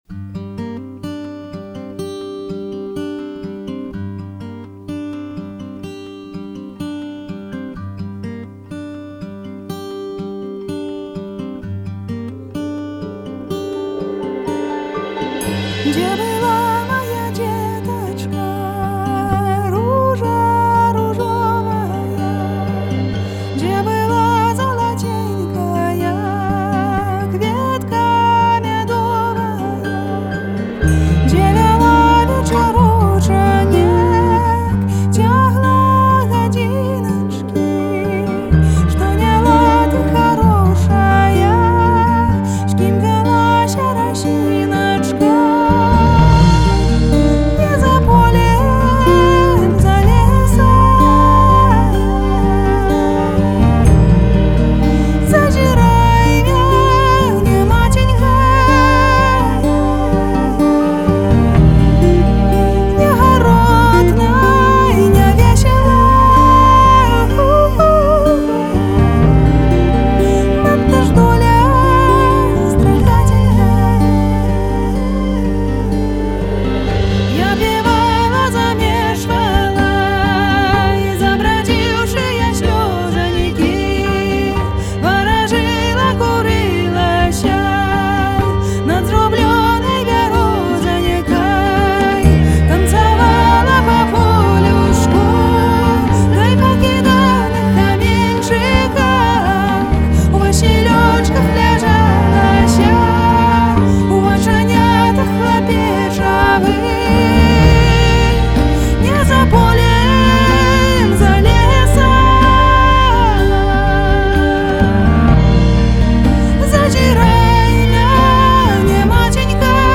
прамоўлены двума галасамі — дзіцячым і дарослым.